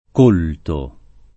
culto [k2lto] s. m. («venerazione; religione») — in Dante, colto [
k1lto]: i folli e i savi Ch’udir parlar di così fatto colto [